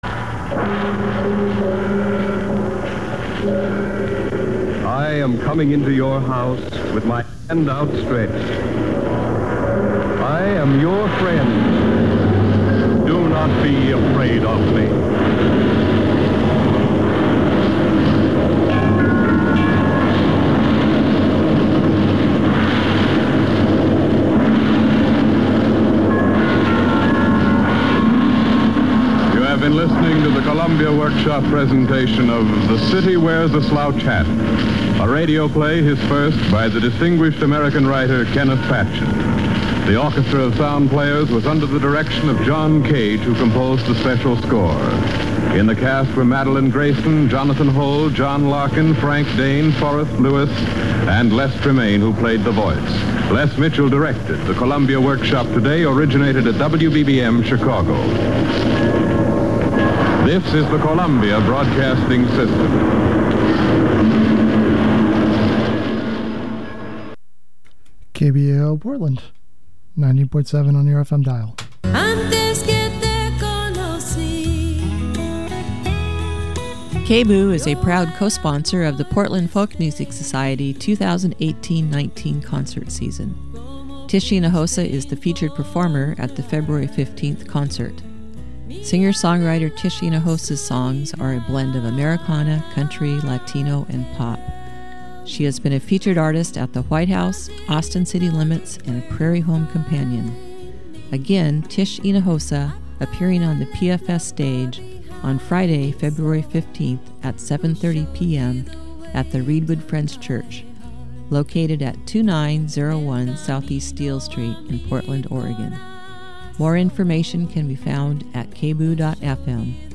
Produced by: KBOO Program:: Talking Earth